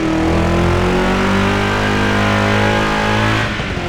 Index of /server/sound/vehicles/lwcars/buggy